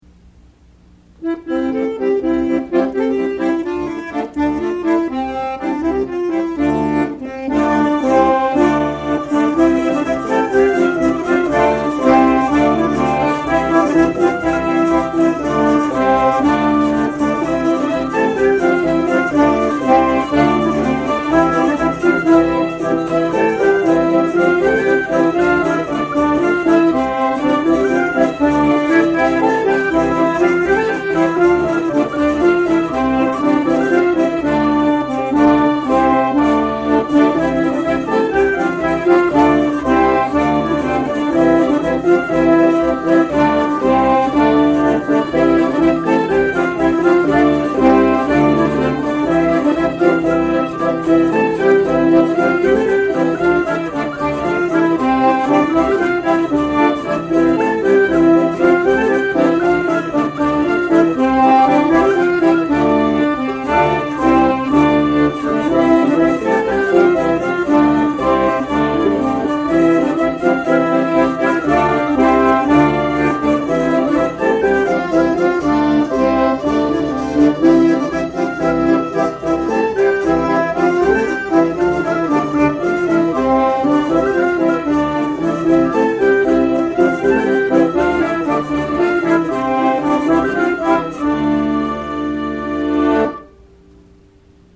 diatonic accordion